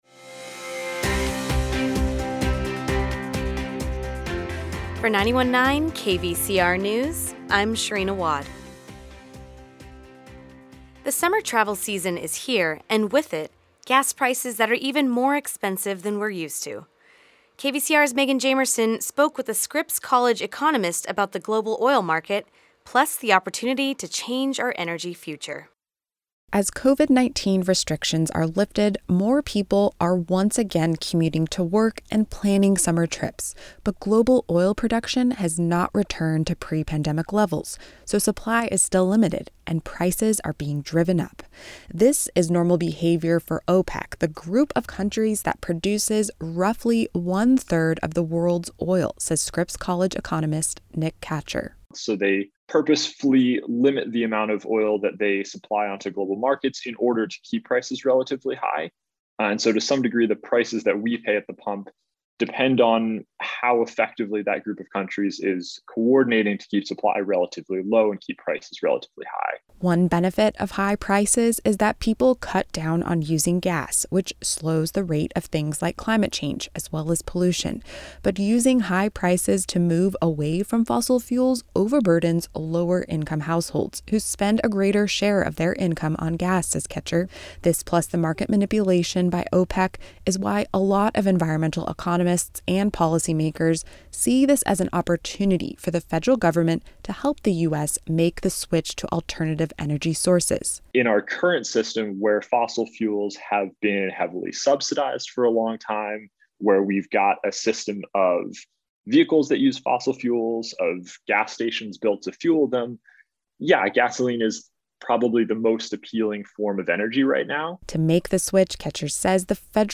The Midday News Report